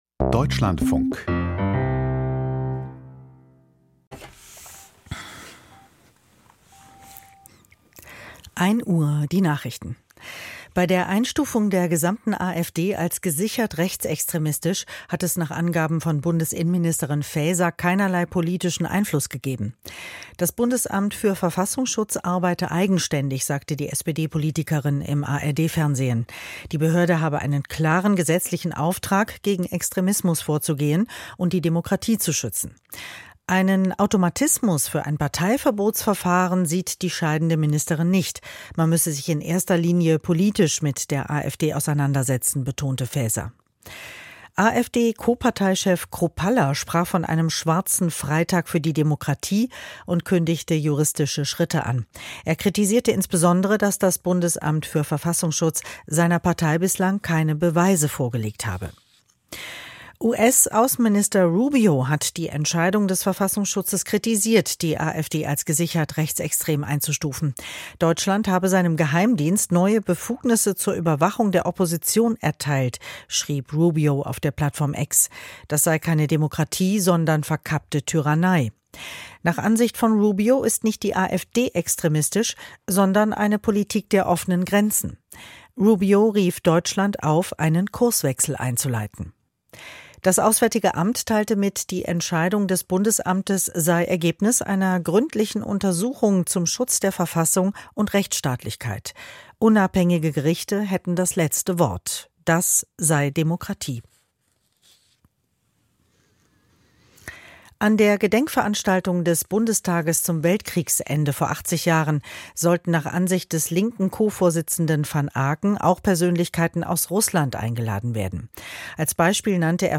Die Deutschlandfunk-Nachrichten vom 03.05.2025, 00:59 Uhr